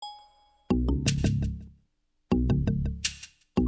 Ambient sound effects